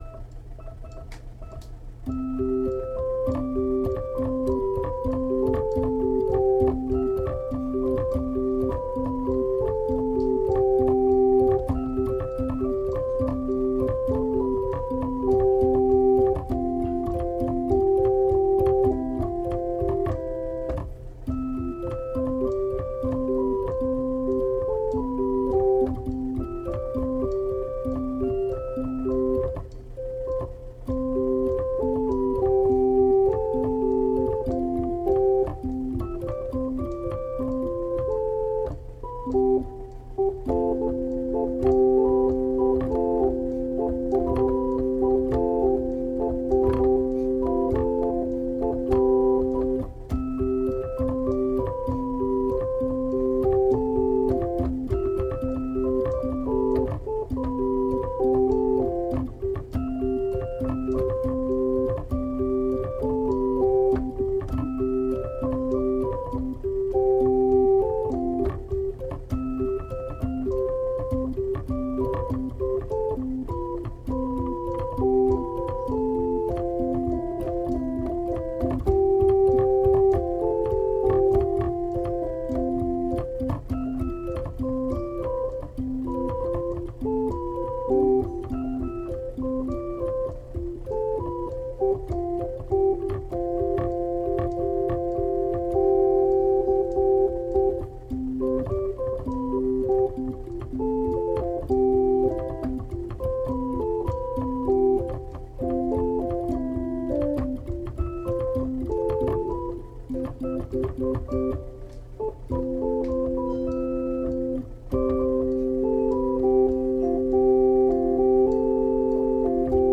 Organ Day 12
I think the title says it all: Nice Happy Melody Oh I can however apologize for the plastic key-press sounds. I think this was recorded after the house ‘bedtime’ so the volume was really low.
Day12_nice_happy_melody.mp3